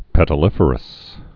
(pĕtl-ĭfər-əs)